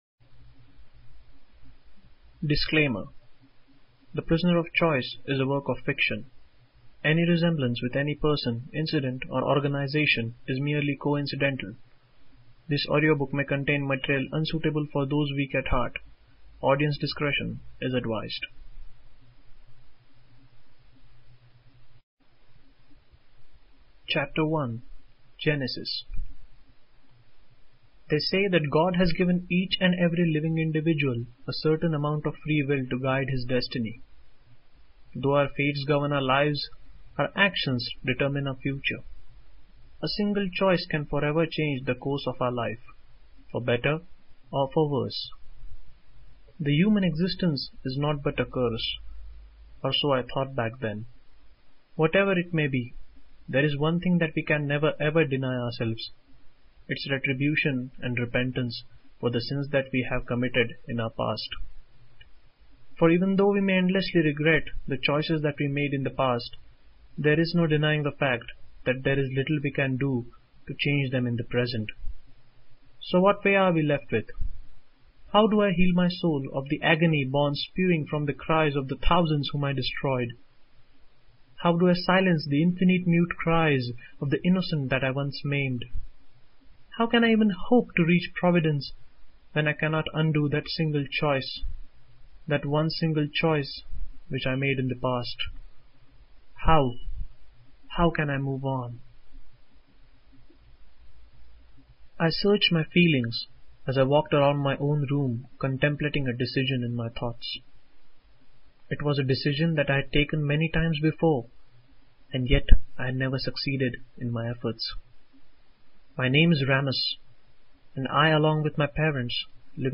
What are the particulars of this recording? Very well produced and read, I think the imagery is powerful and creates a strong impression.